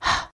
This unvoiced consonant says: /h/, /h/, /h/ hat.
H-hat-phoneme-AI.mp3